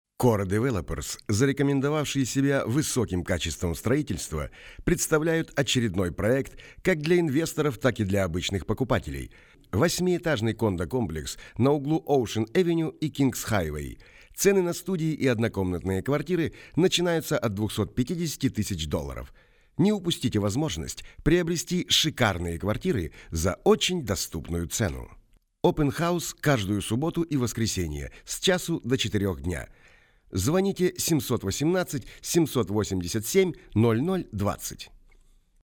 сырая начитка Категория: Аудио/видео монтаж
начитка, запись